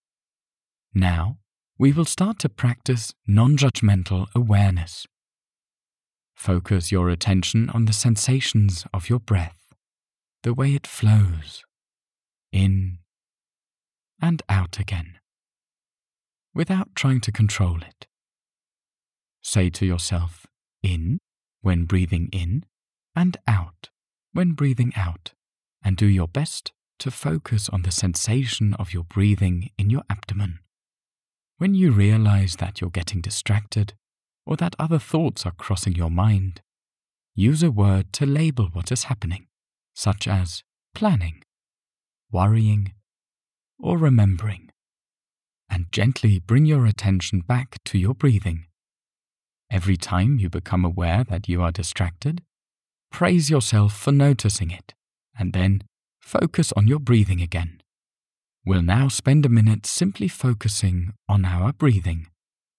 Seine Stimme ist warm, jung, dynamisch, authentisch, natürlich.
Sprechprobe: eLearning (Muttersprache):
His voice is warm, young, upbeat, credible, natural.